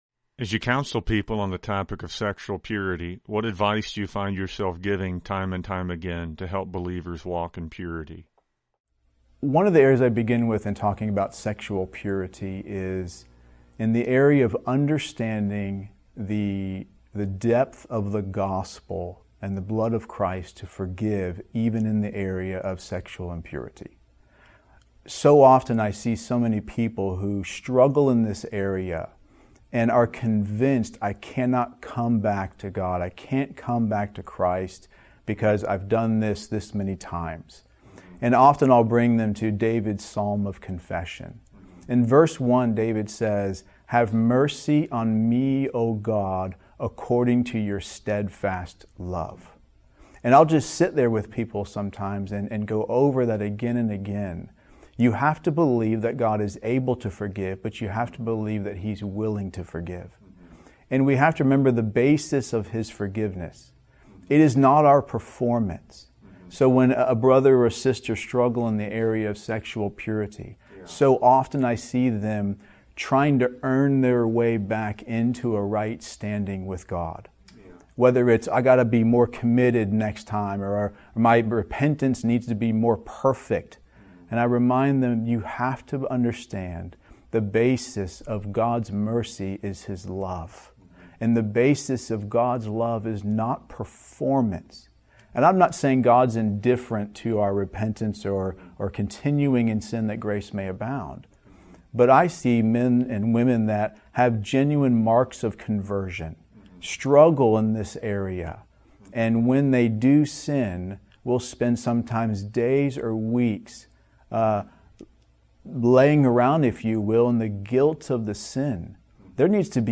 Interview | 5:41 | To walk in purity, the believer must not just focus on guarding themselves from the sin; but they must be actively pursuing Christ and being satisfied in Him.